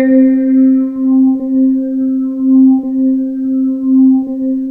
SWEEP   C3-L.wav